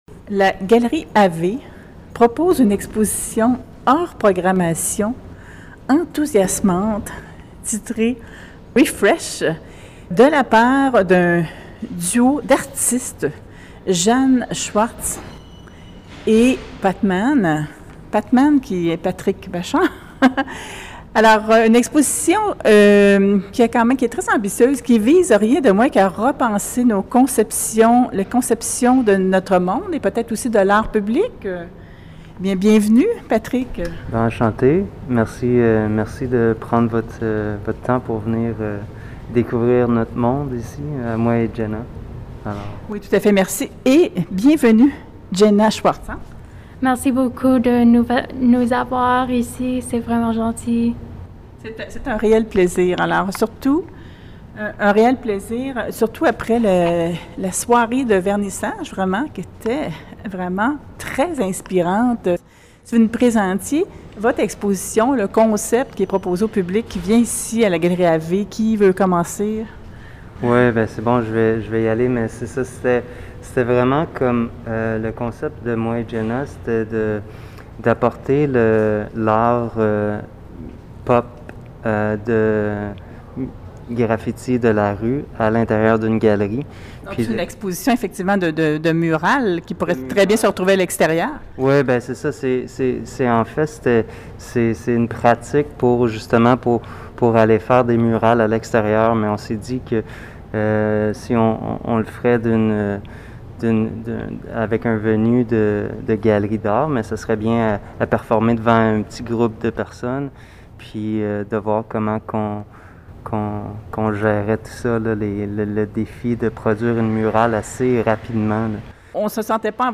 TROIS ARTISTES VISUELS ÉMERGENTS AYANT EXPOSÉ À LA GALERIE AVE DURANT L’ÉTÉ 2020, INTERVIEWÉS PAR IN SITU :